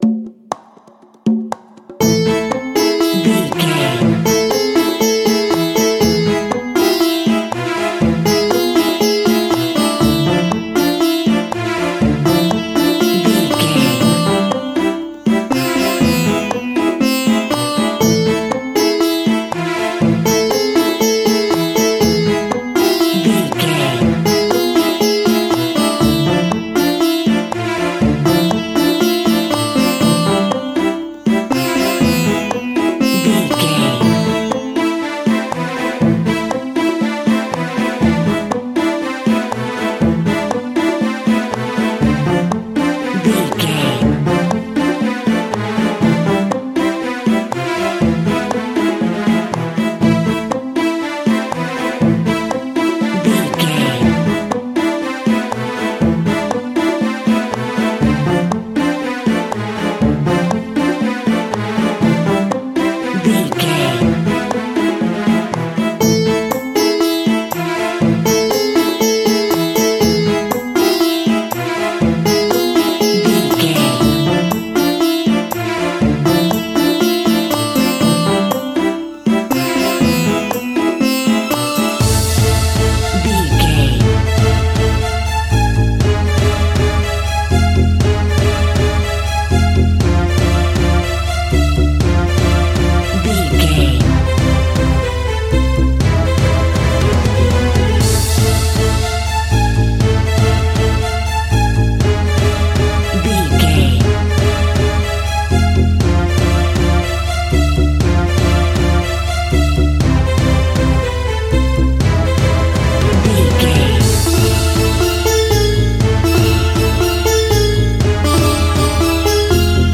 Aeolian/Minor
instrumentals
World Music
percussion